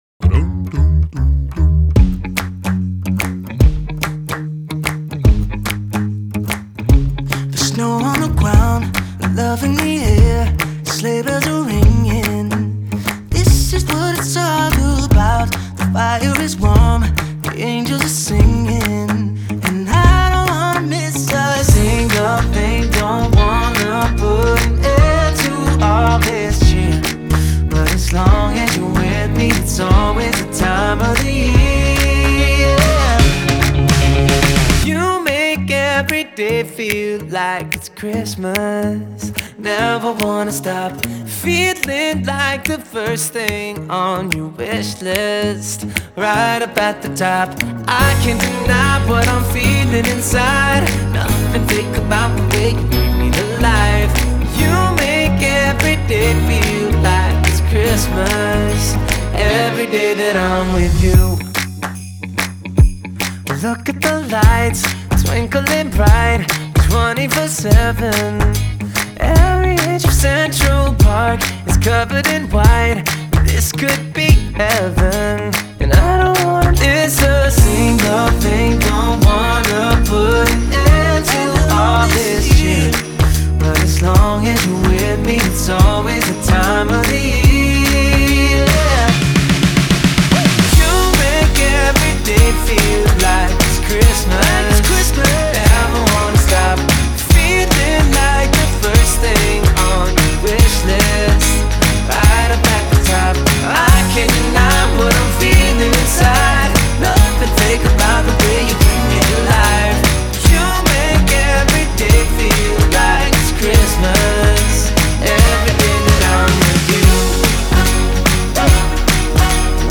праздничная поп-песня